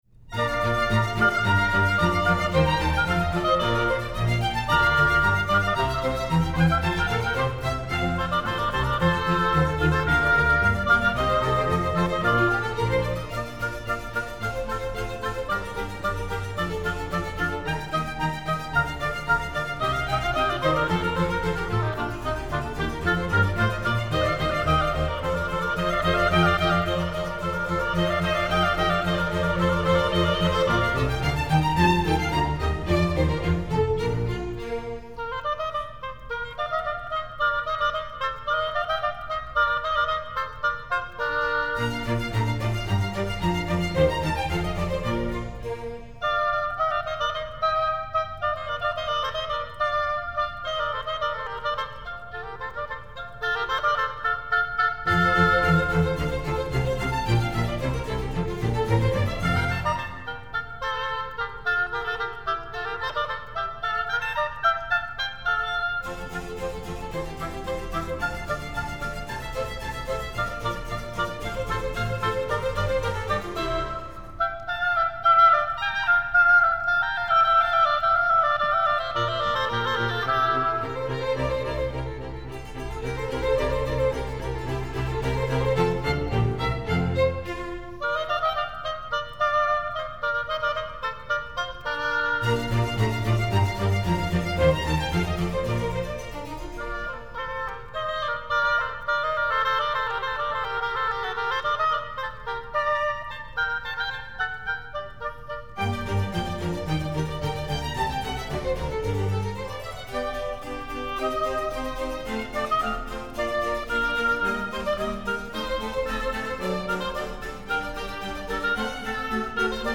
4Handel_Allegro.mp3